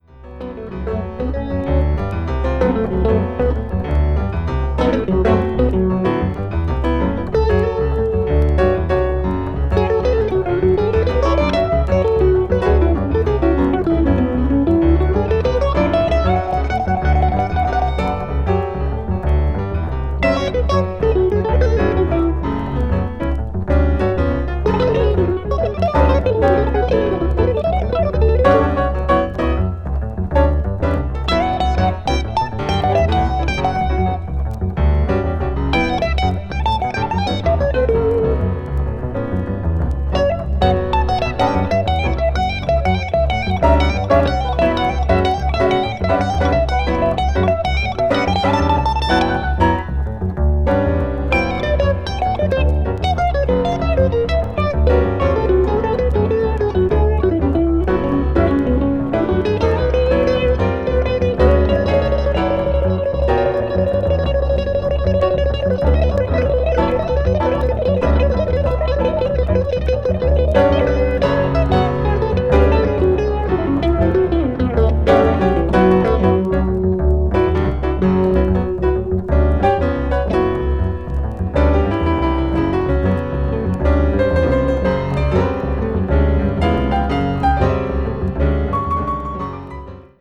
media : VG+/VG+(細かいスリキズによるわずかなチリノイズ/軽いチリノイズが入る箇所あり)
avant-jazz   contemporary jazz   deep jazz